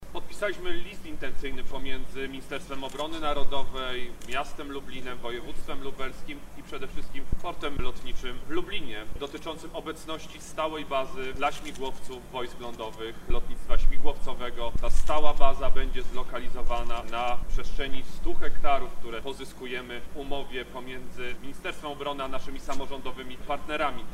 Władysław Kosiniak-Kamysz-mówi Władysław Kosiniak-Kamysz